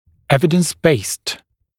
[‘evɪdəns beɪst][‘эвидэнс бэйст]основанный на существующих доказательствах